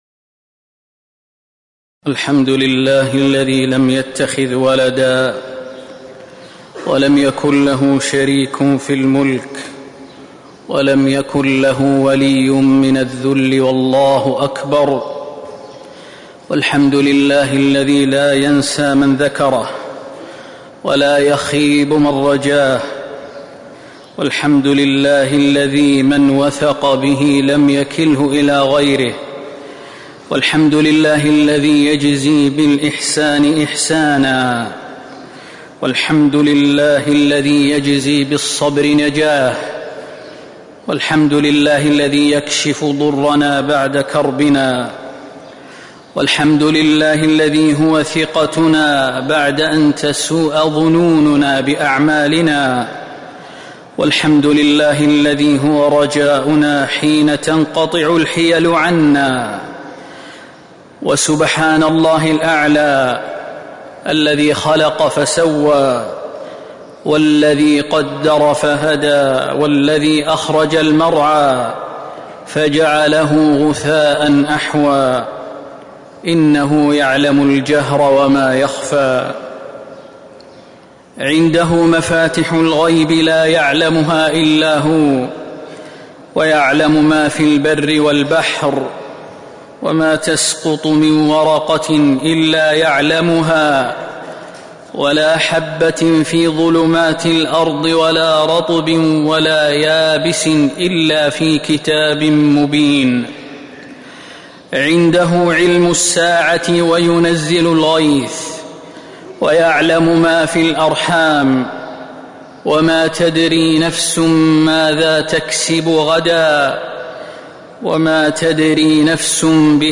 خطبة الاستسقاء - المدينة - الشيخ خالد المهنا
تاريخ النشر ٢٦ جمادى الأولى ١٤٤٦ هـ المكان: المسجد النبوي الشيخ: فضيلة الشيخ د. خالد بن سليمان المهنا فضيلة الشيخ د. خالد بن سليمان المهنا خطبة الاستسقاء - المدينة - الشيخ خالد المهنا